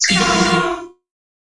游戏音效 " FX203
描述：爆炸哔哔踢游戏gameound点击levelUp冒险哔哔sfx应用程序启动点击
Tag: 爆炸 单击 冒险 游戏 应用程序 点击的LevelUp 启动 gamesound 哔哔声 SFX